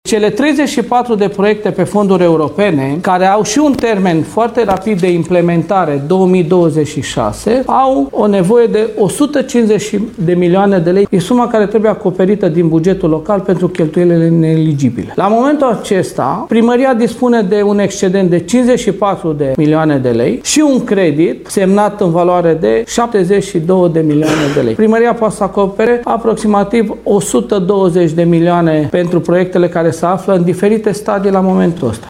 Primarul municipiului Lugoj, Călin Dobra, spune că suma care trebuie acoperită de la bugetul local nu poate fi asigurată în acest moment.